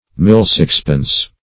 Search Result for " mill-sixpence" : The Collaborative International Dictionary of English v.0.48: Mill-sixpence \Mill"-sixpence\, n. A milled sixpence; -- the sixpence being one of the first English coins milled (1561).